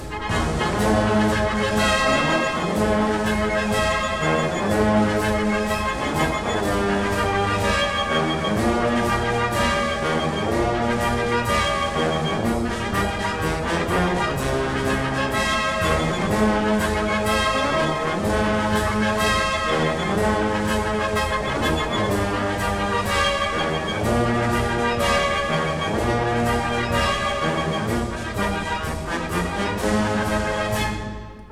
A 1959 stereo recording